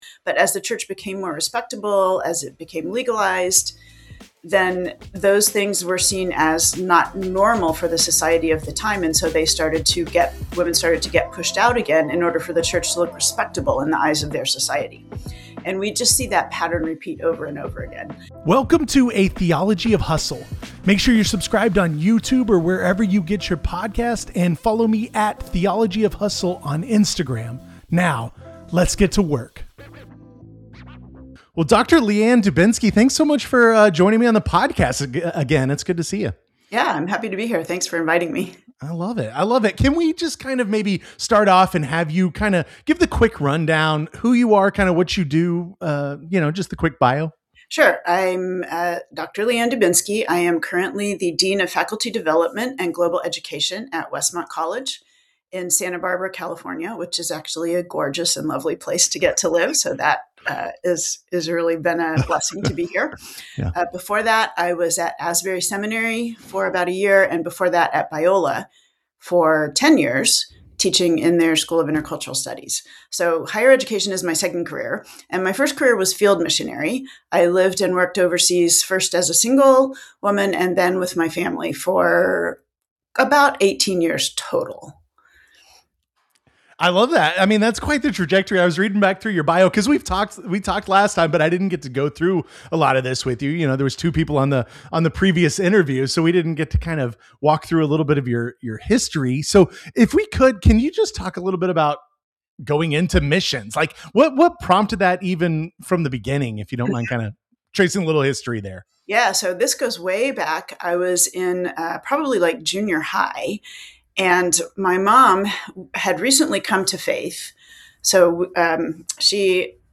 What happens when the workplace has a stained glass partition? In this conversation